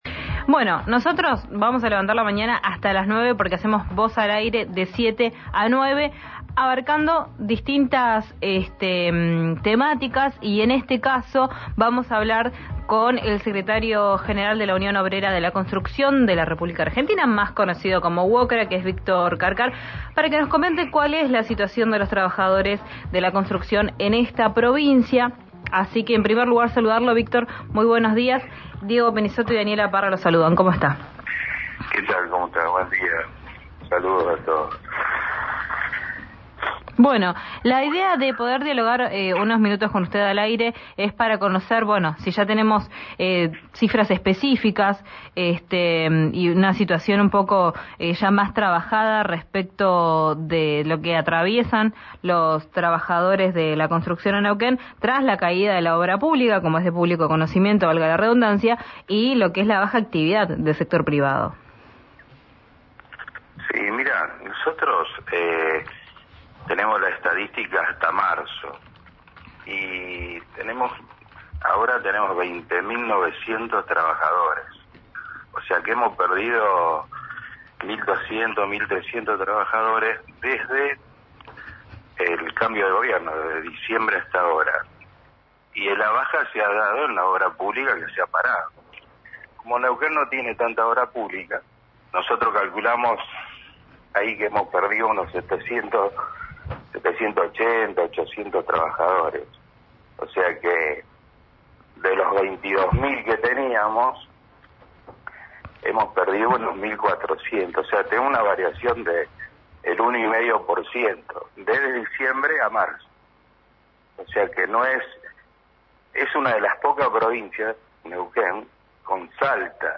en diálogo con RÍO NEGRO RADIO.